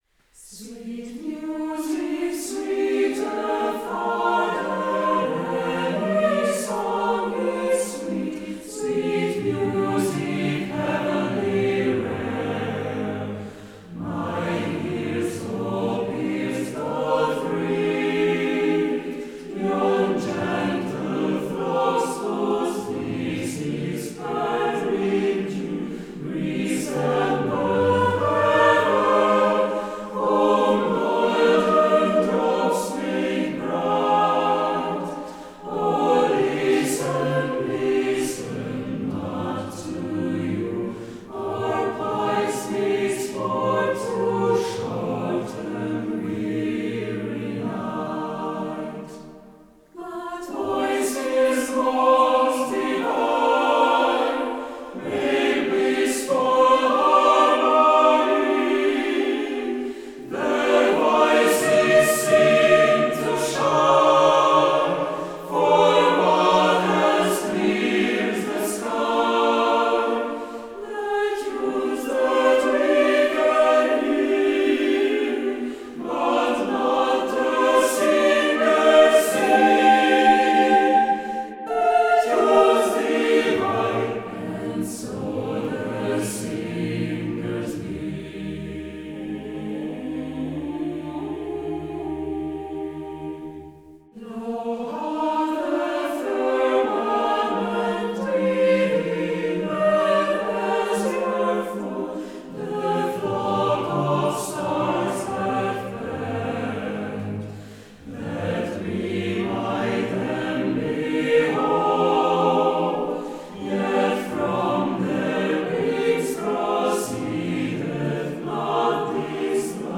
Mixed Choir Tempo - Medium BPM - 94